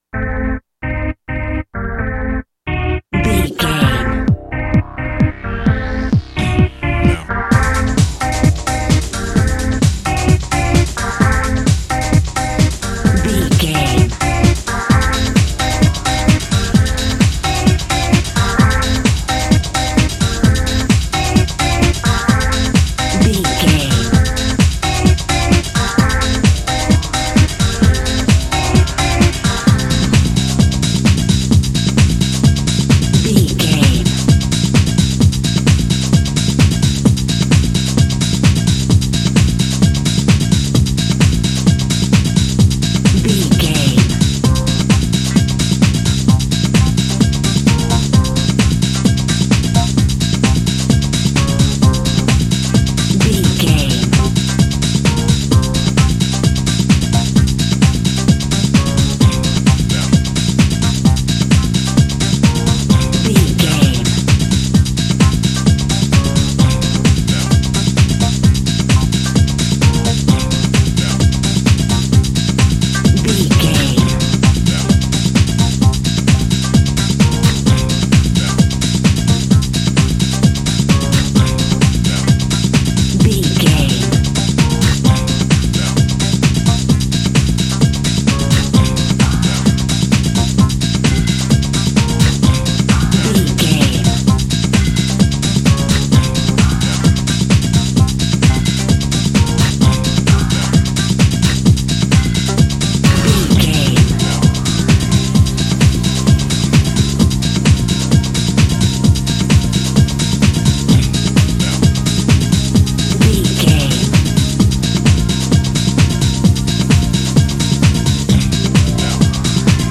Aeolian/Minor
synthesiser
drum machine
Eurodance